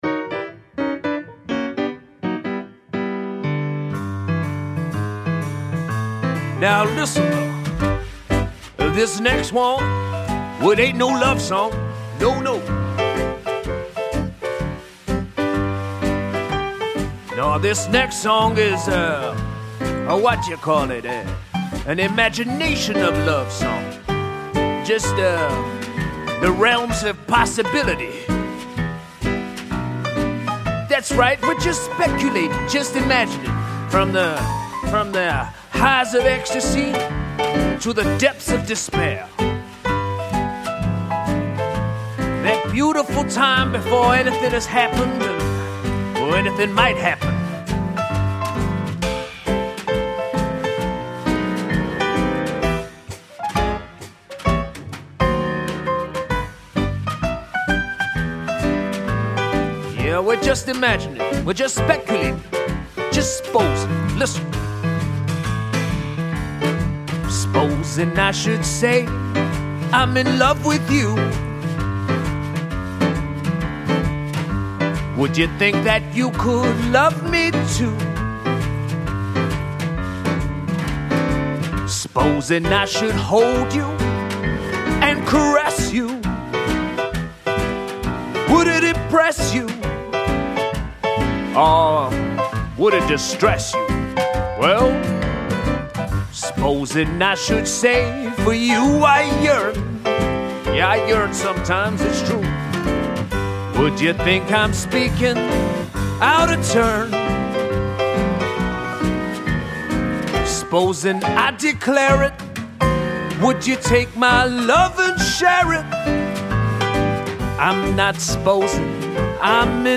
Vocals / Guitar, Piano / Clarinet, Percussion / Drums